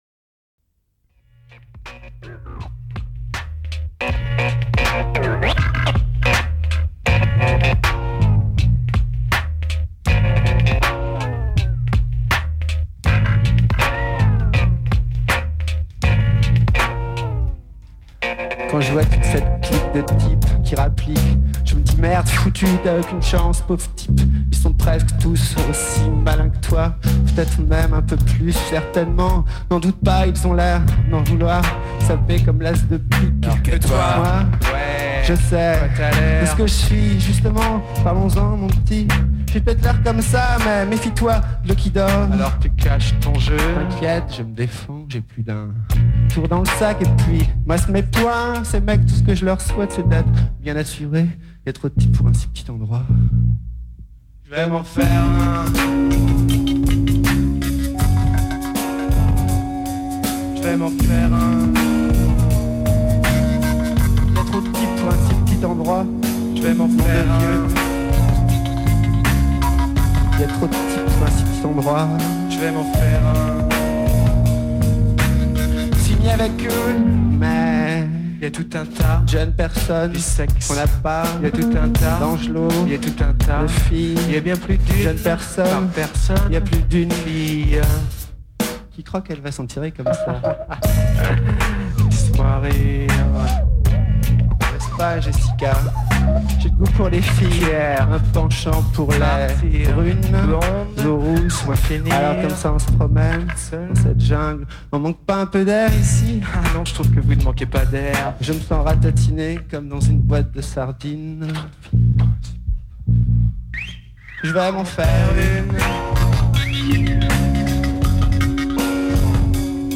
enregistrée le 12/03/2001  au Studio 105